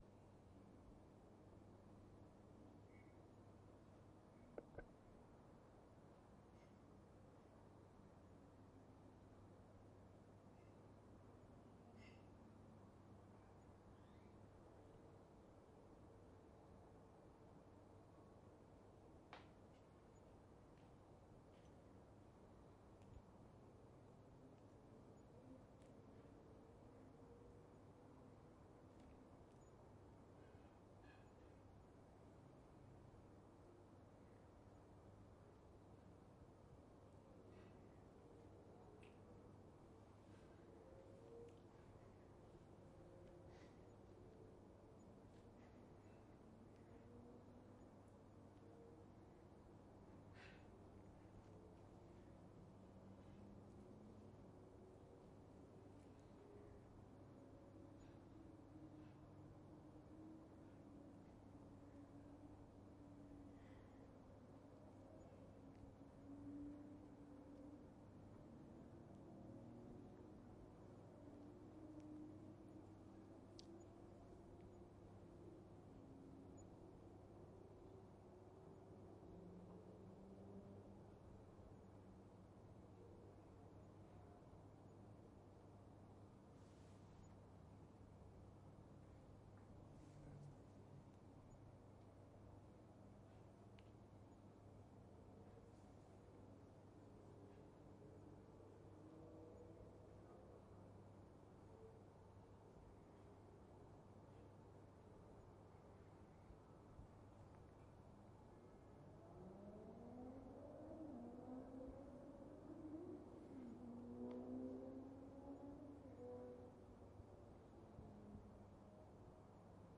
AMB安静的城市(夜间)
描述：在夜晚安静的城市环境中录制的氛围。微妙的，有微弱的蟋蟀和夜鸟。
Tag: 微妙 环境 晚上 蟋蟀 住宅 花园 OWI 城市